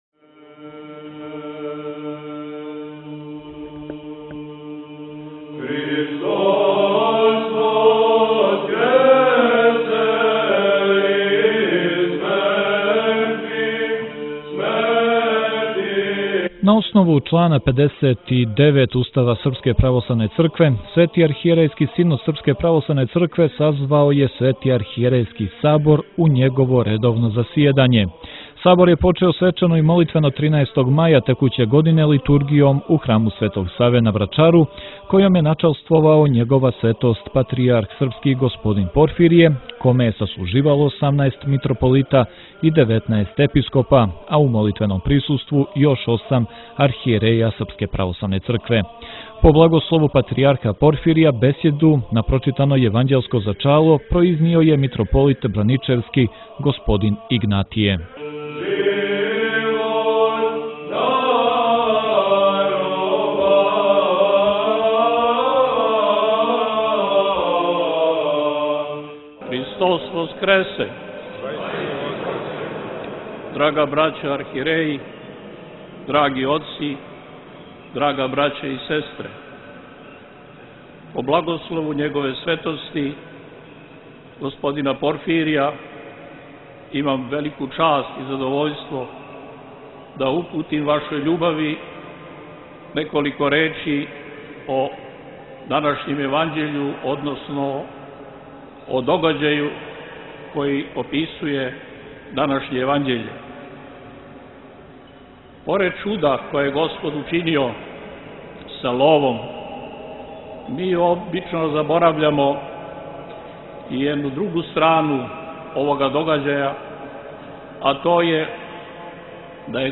Његова светост Патријарх српски г. Порфирије је служио 6. јула 2025. године Свету литургију на Тргу слободе у Невесињу, поводом […]